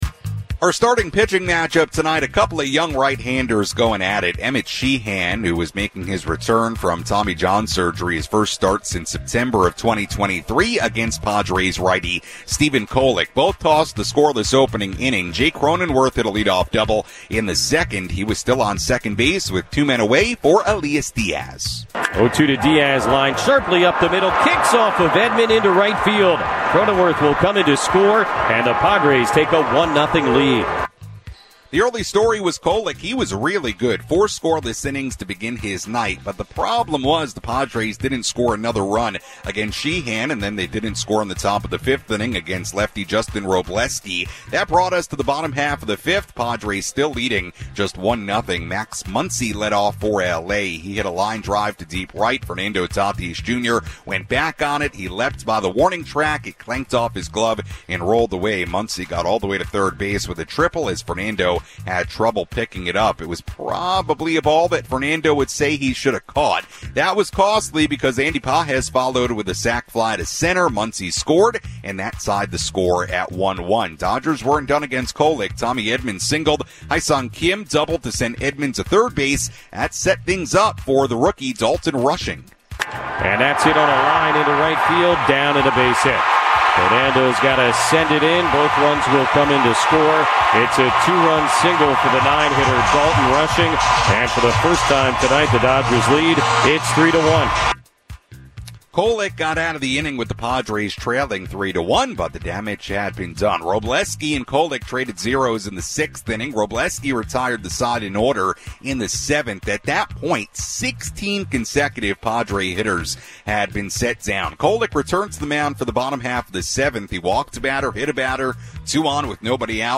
along with play-by-play highlights.